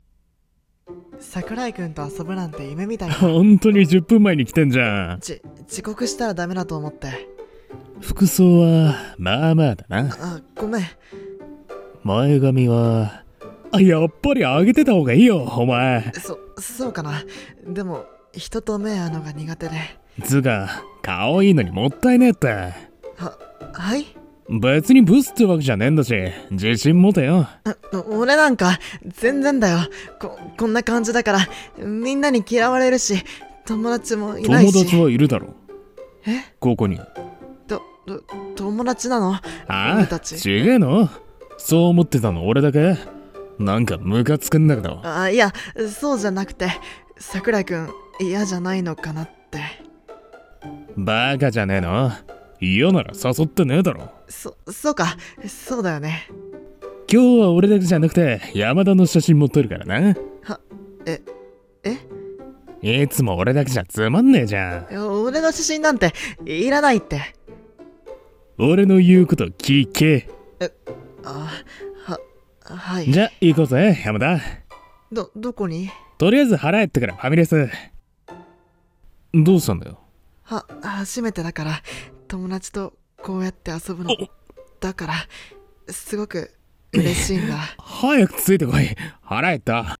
長編BL台本】綺麗な君に恋をした